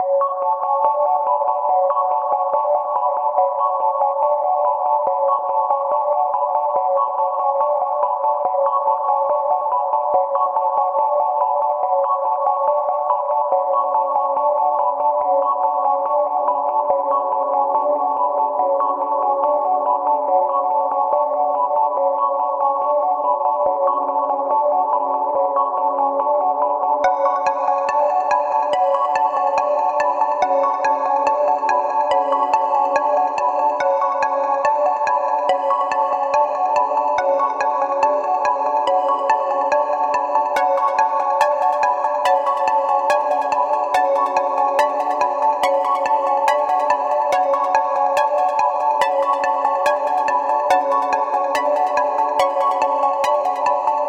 SOUTHSIDE_melody_loop_sky_142_F#m.wav